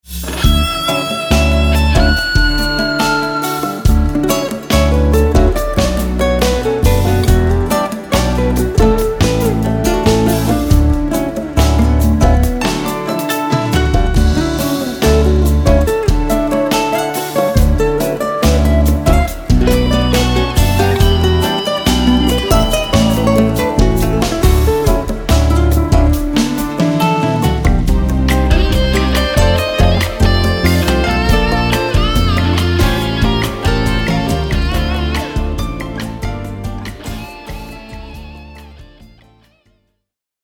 guitars, percussion
drums
bass
keyboards